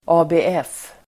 Ladda ner uttalet
ABF förkortning, ABF Uttal: [a:be:'ef:] Definition: Arbetarnas Bildningsförbund (Workers' Educational Association) Förklaring: Studieförbund som anordnar studiecirklar i den kommun där det finns representerat.